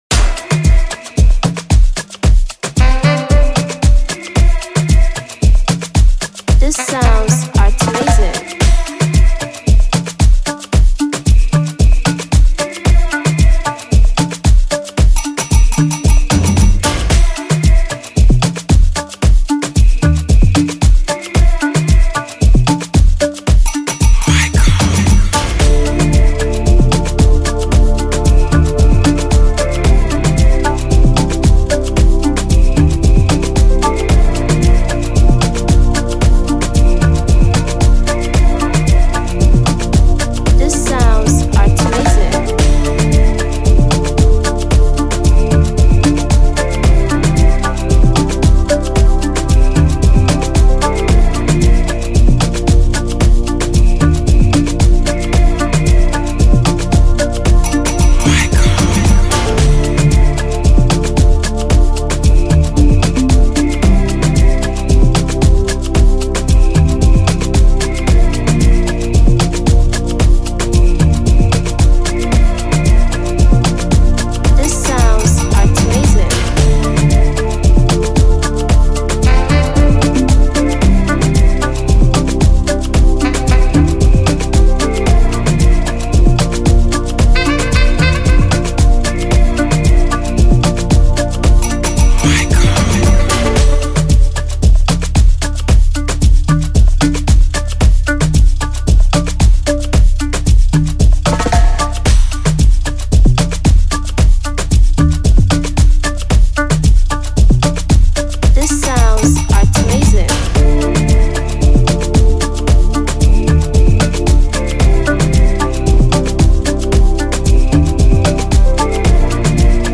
remake free beat Instrumental